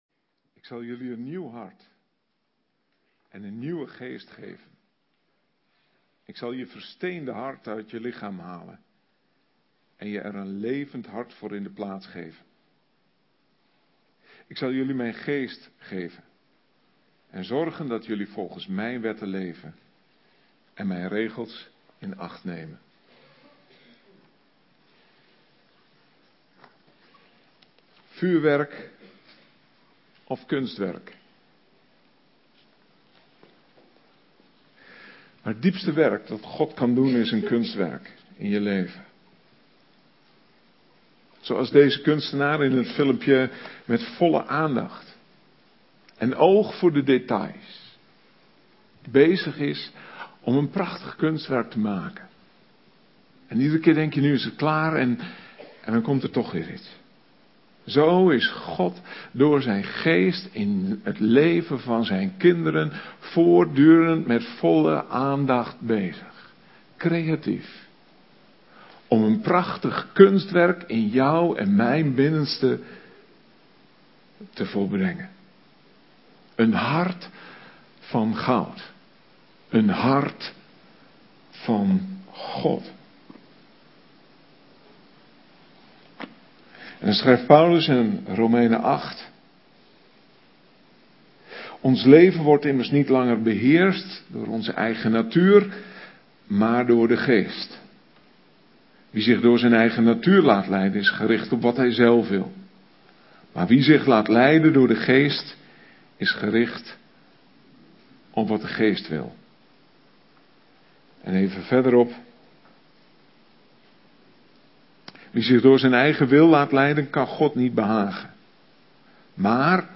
Gezamelijke dienst met Berea Noord